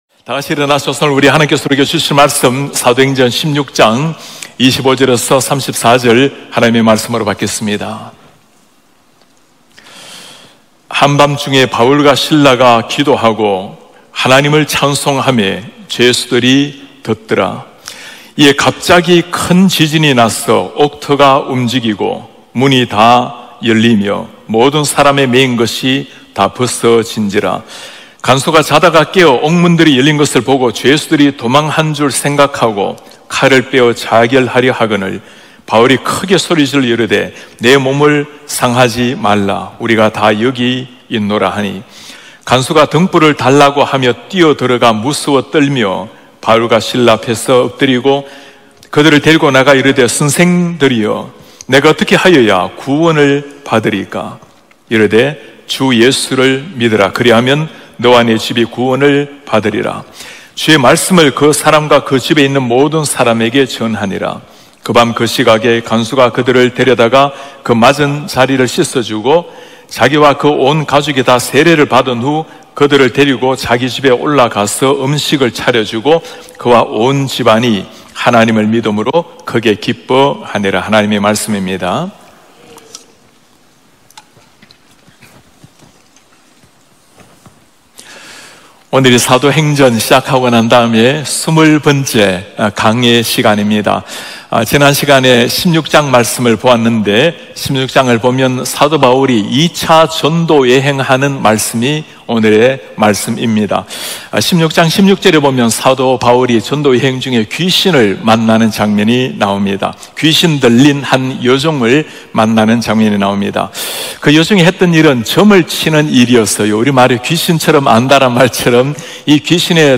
예배: 금요비상기도회